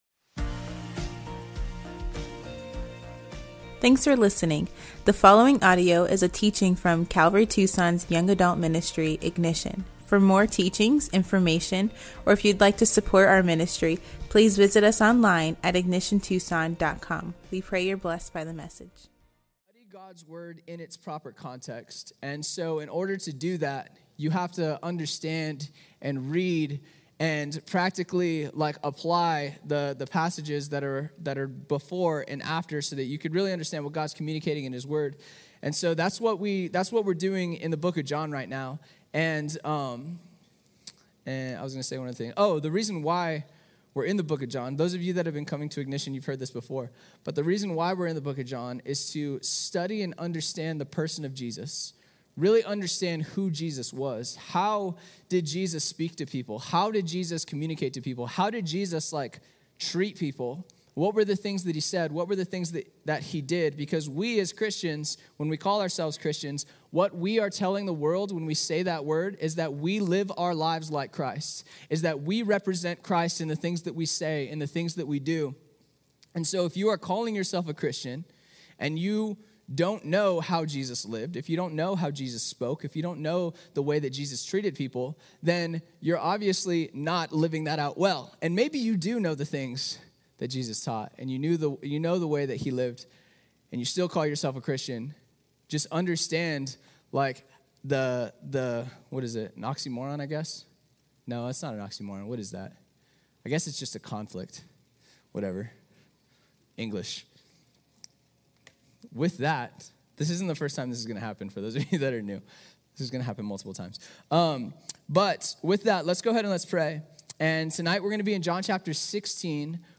at Ignition Young Adults on October 11, 2024